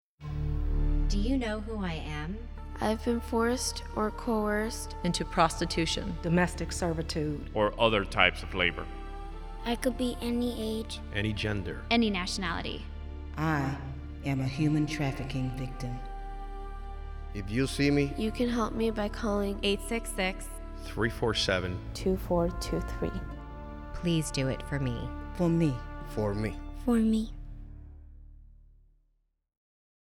WASHINGTON — U.S. Immigration and Customs Enforcement's (ICE) Homeland Security Investigations (HSI) today announced the start of a national radio public service announcement (PSA) campaign to generate awareness about human trafficking.
ht-audio-psa-english.mp3